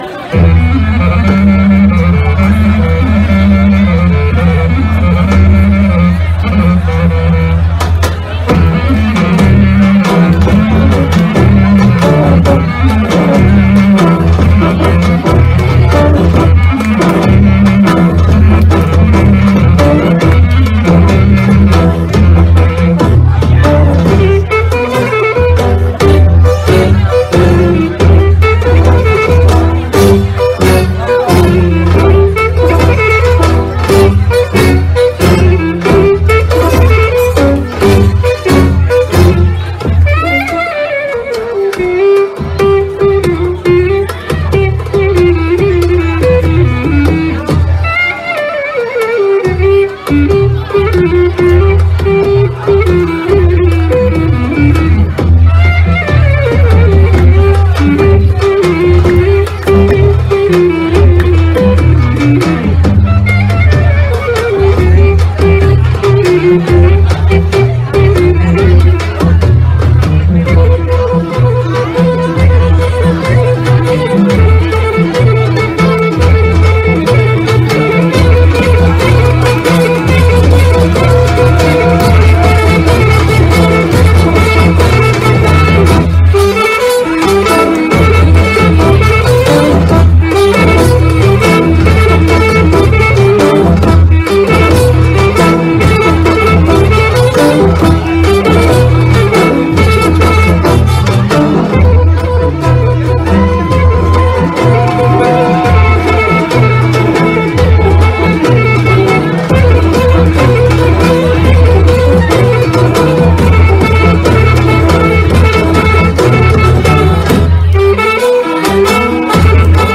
Gig Recordings